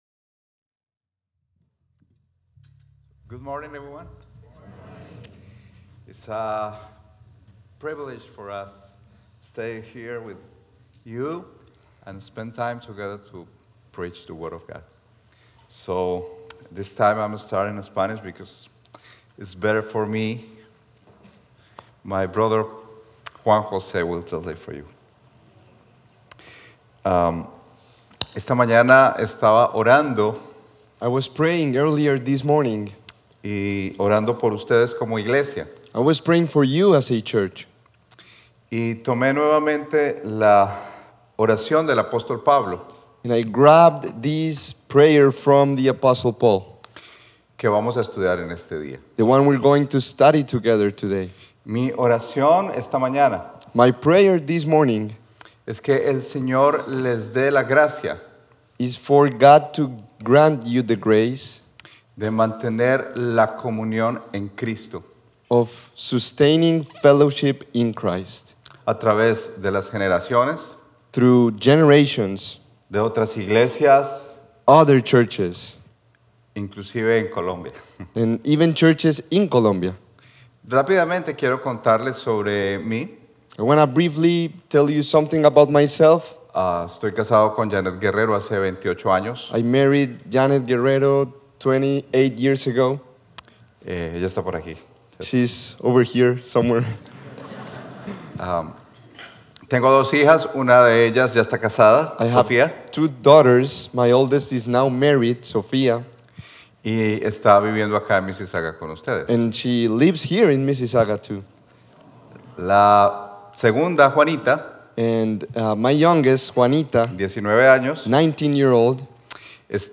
Sermon Notes 1.